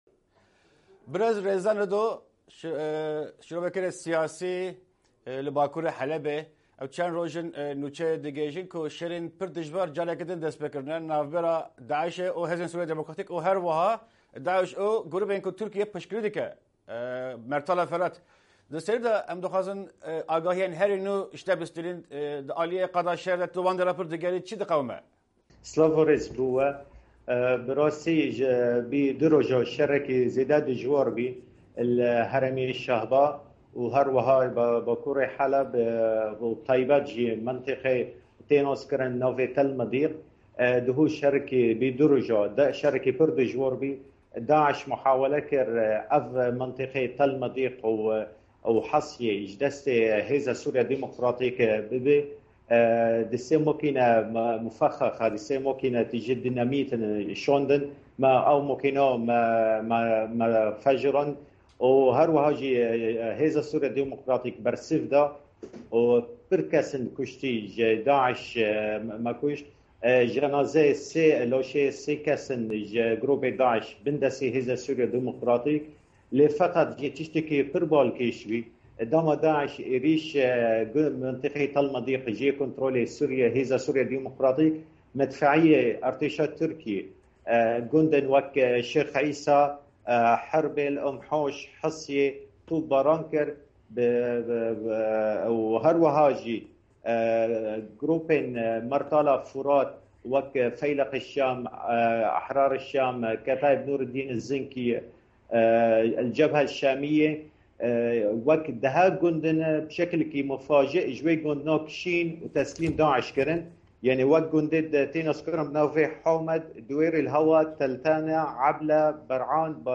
hevpeyvîna taybet